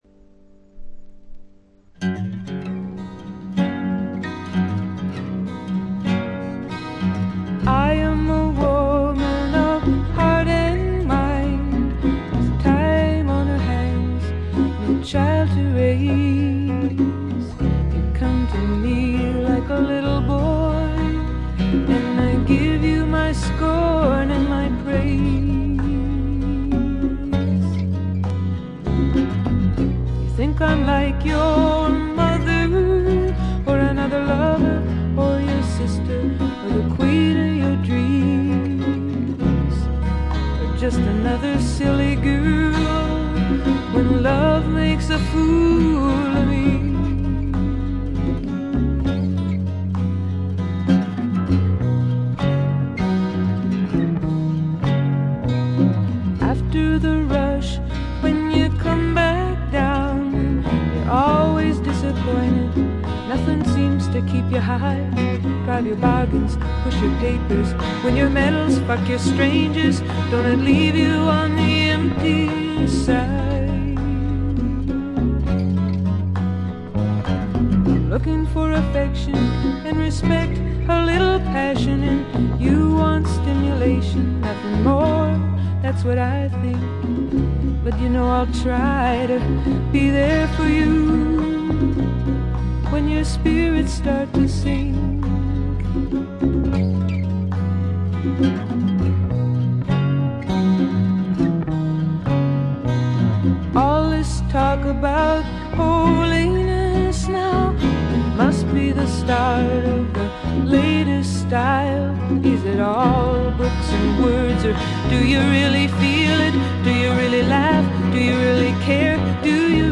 わずかなノイズ感のみ。
試聴曲は現品からの取り込み音源です。
Recorded At - A&M Studios